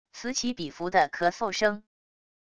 此起彼伏的咳嗽声wav音频